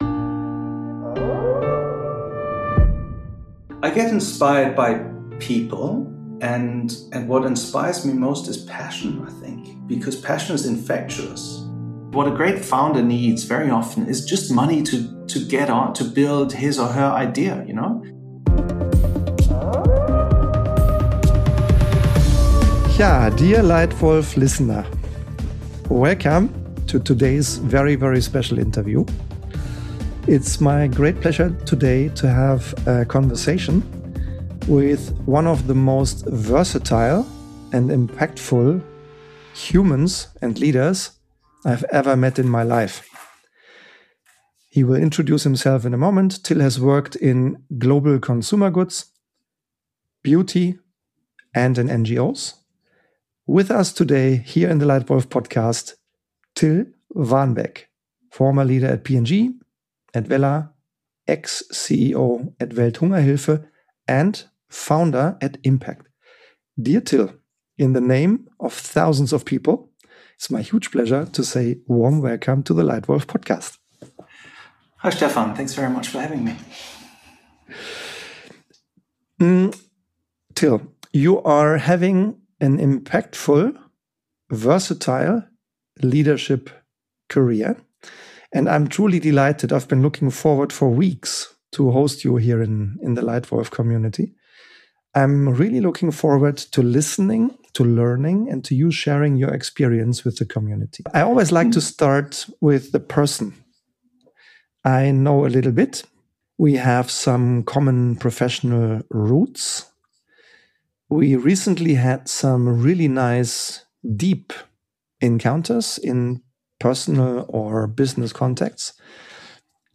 A conversation about leadership across worlds, about creating impact beyond systems, and about what truly matters when it counts.